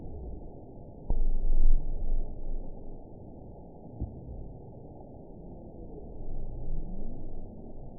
event 920969 date 04/21/24 time 00:13:05 GMT (1 year, 1 month ago) score 8.05 location TSS-AB05 detected by nrw target species NRW annotations +NRW Spectrogram: Frequency (kHz) vs. Time (s) audio not available .wav